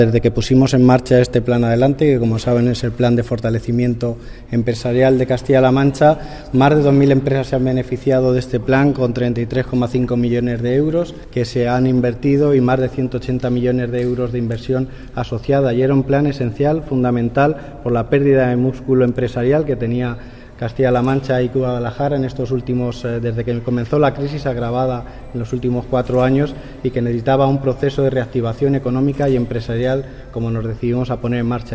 El director general de Empresas, Competitividad e Internacionalización, Javier Rosell, habla del resultado del Plan Adelante Empresas puesto en marcha por el Gobierno regional.